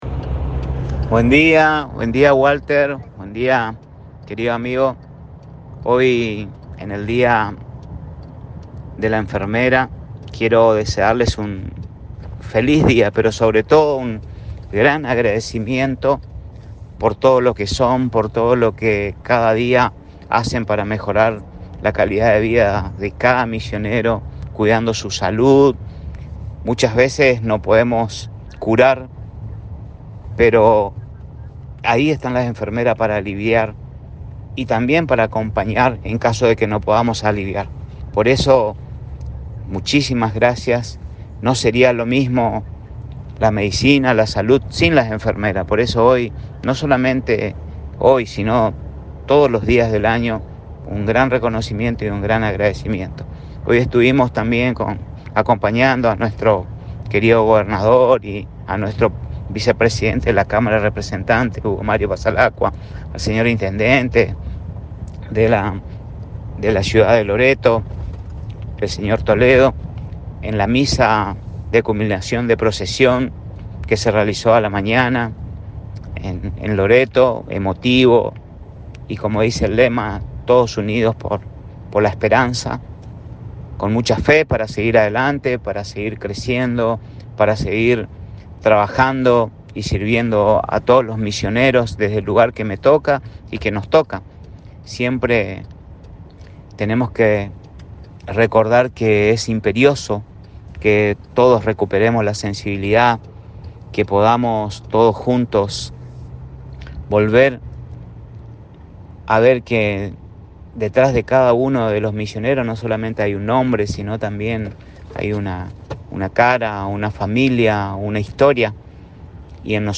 Salutación del Ministro Alarcón por el Día de la Enfermería - Agencia de Noticias Guacurari